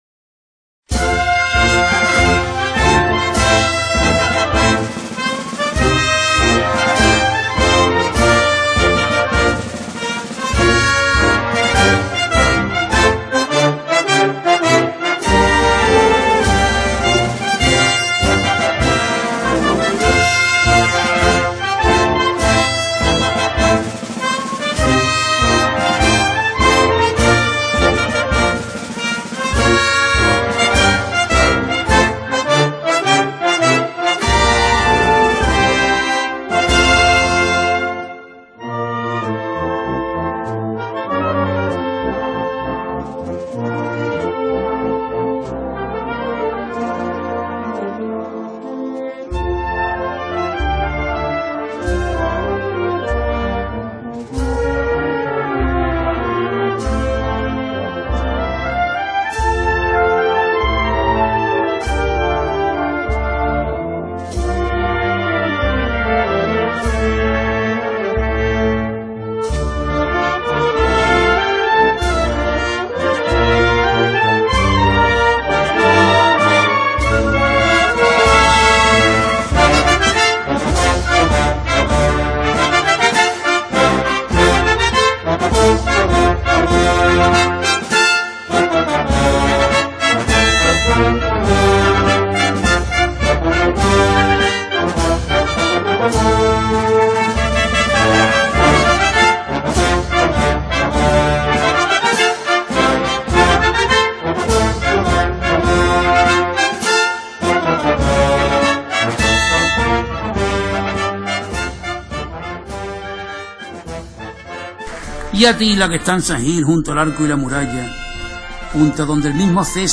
una entrega semanal de sevillanas para la historia.
Temática: Cofrade